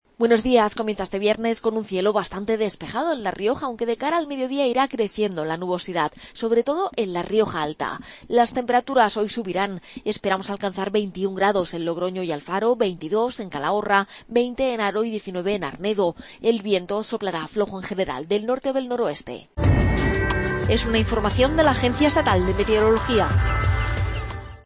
AUDIO: Pronóstico.